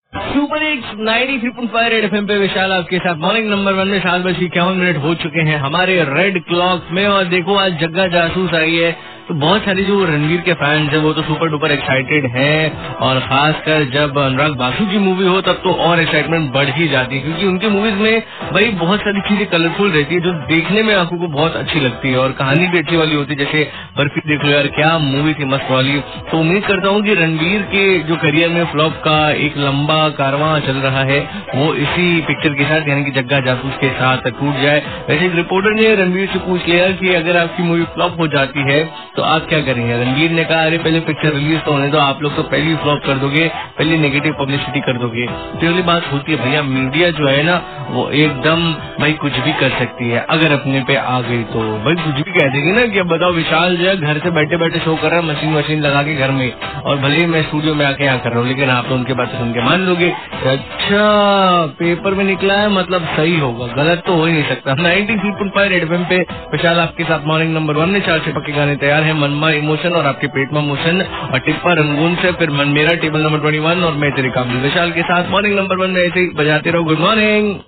RJ TALKING ABOUT JAGGA JASOOS